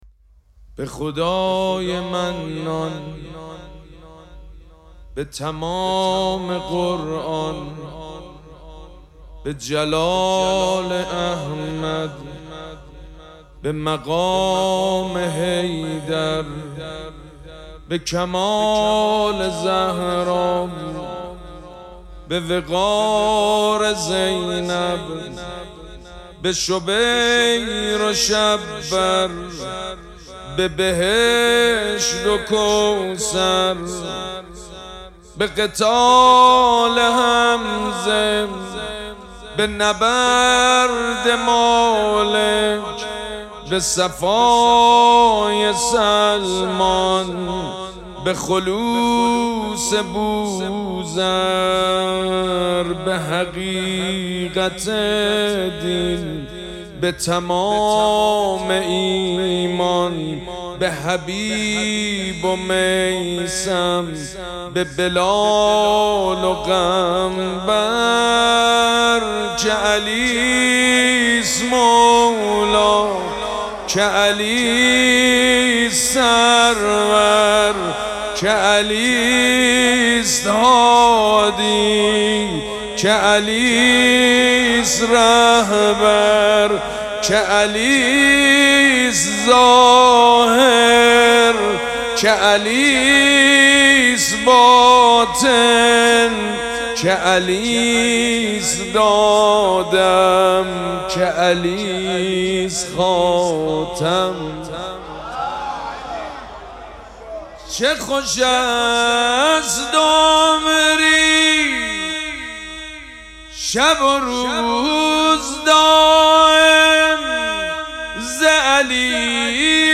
مراسم عزاداری وفات حضرت ام‌البنین سلام‌الله‌علیها
مدح
حاج سید مجید بنی فاطمه